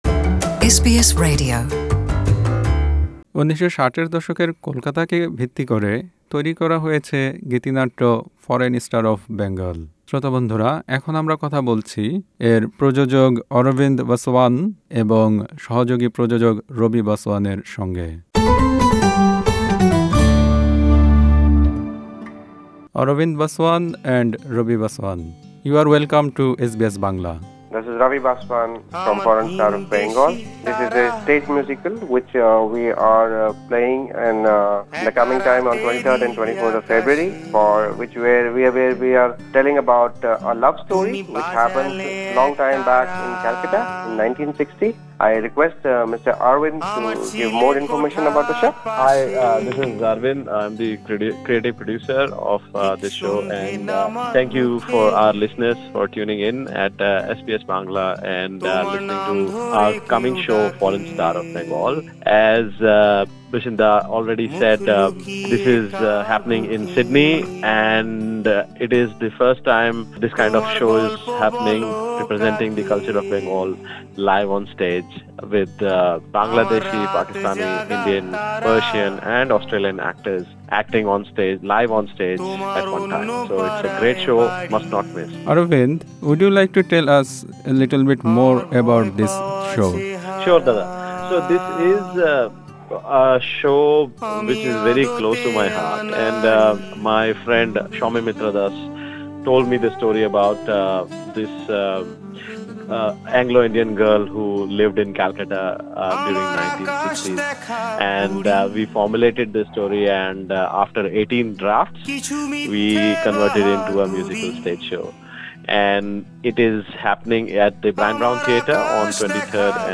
সাক্ষাৎকার শুনুন ইংরেজিতে